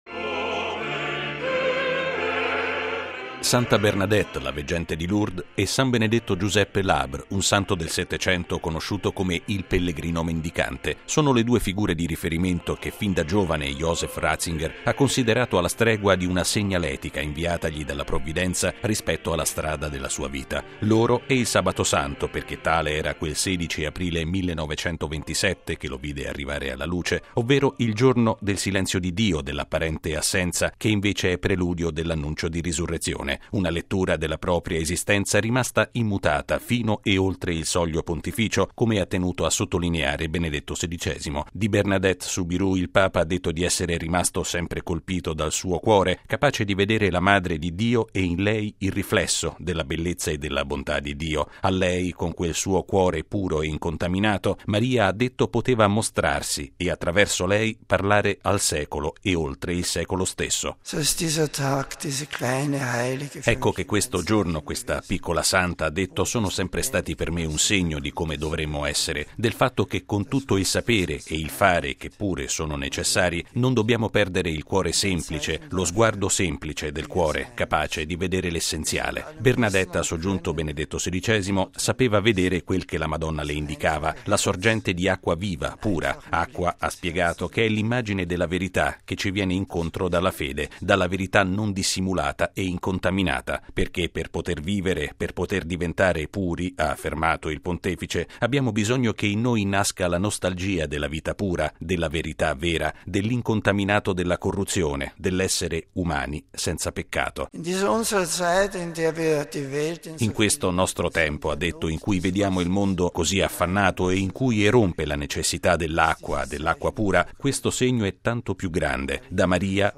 ◊   Una Messa celebrata in privato nella Cappella Paolina del Palazzo apostolico, alla presenza di vescovi tedeschi e di personalità della Baviera.